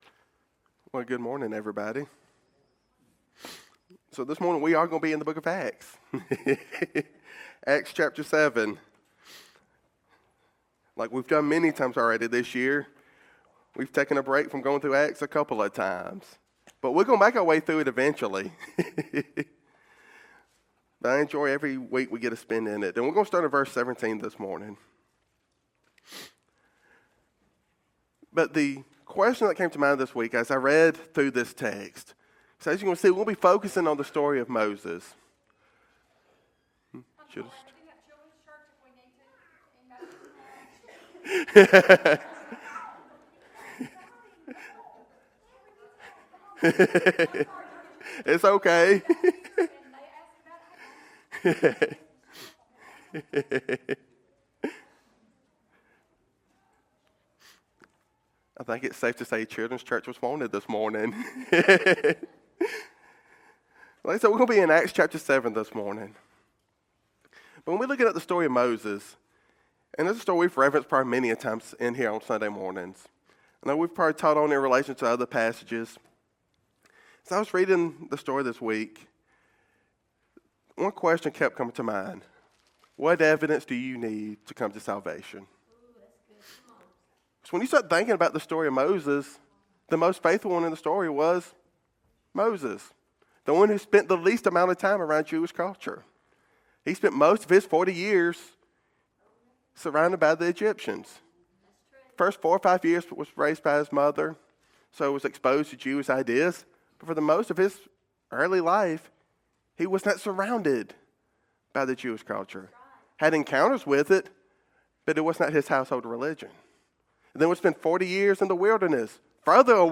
While we live in a world obsessed with logic, science, and historical proofs, the sermon reminds us that intellectual arguments alone do not save.